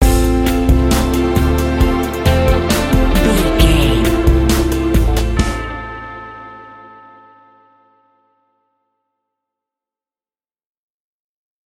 Aeolian/Minor
drums
electric guitar
bass guitar
haunting